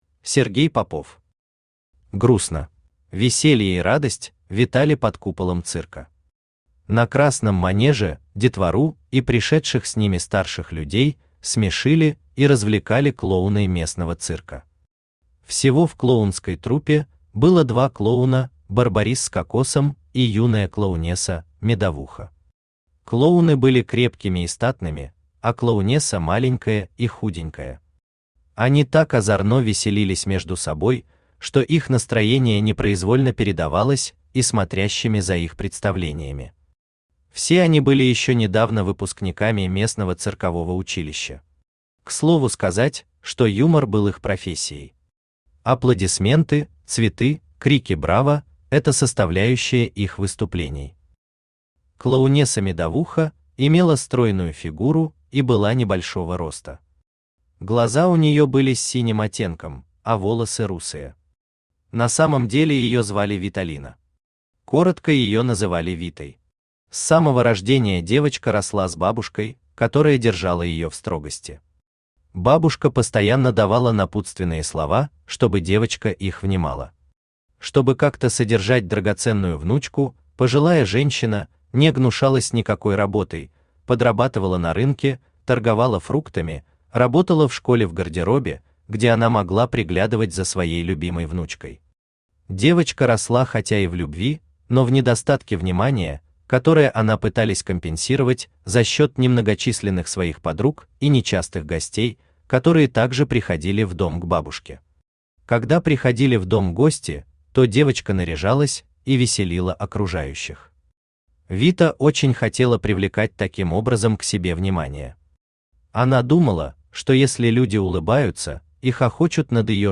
Aудиокнига Грустно Автор Сергей Андреевич Попов Читает аудиокнигу Авточтец ЛитРес.